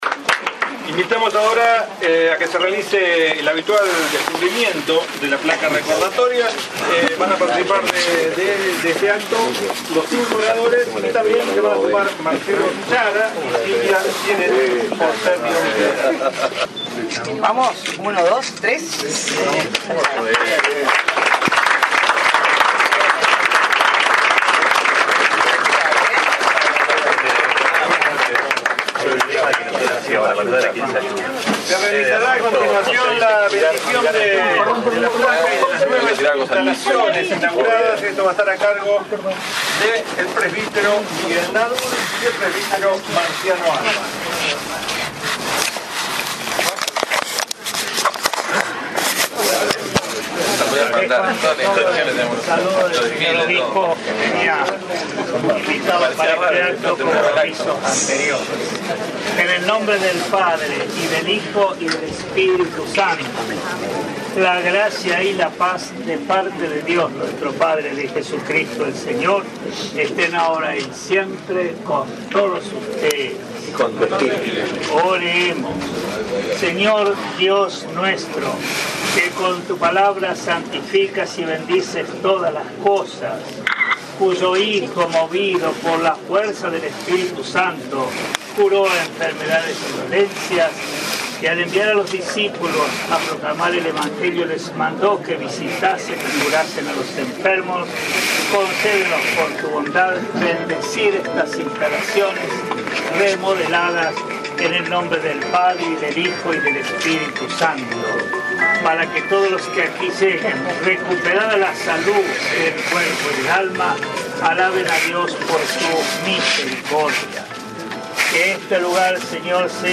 Se realizó un descubrimiento de placa y recorrida de las instalaciones con motivo de la finalización de las obras de puesta en valor del Servicio de Clínica y de Cirugía del Hospital, llevadas a cabo gracias al aporte solidario de la Maratón 10K Ternium realizada en octubre del año pasado.
Audio: Descubrimiento de la placa recordatoria y bendición.
5-BENDICION.mp3